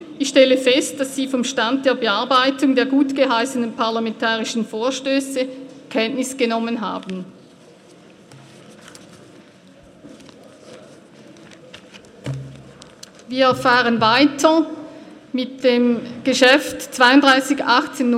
Ratspräsidentin, stellt Kenntnisnahme vom Bericht Stand der Bearbeitung der gutgeheissenen parlamentarischen Vorstösse fest.
Session des Kantonsrates vom 11. bis 13. Juni 2018